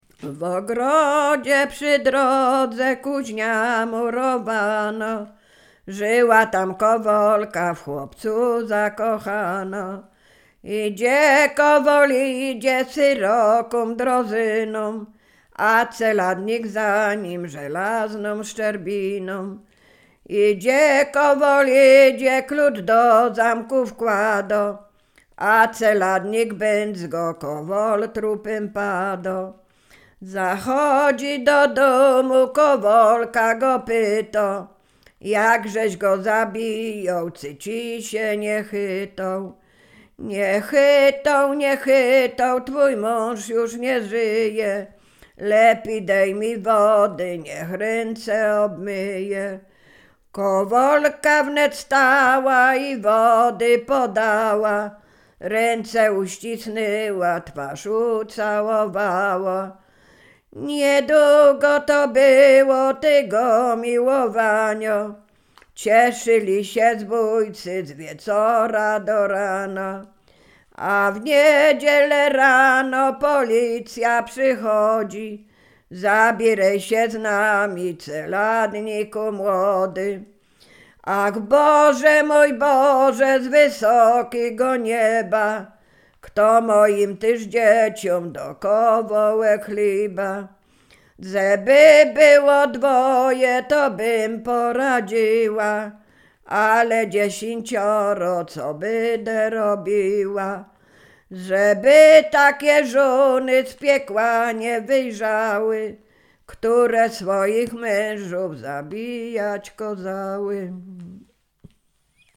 Sieradzkie
Ballada
ballady dziadowskie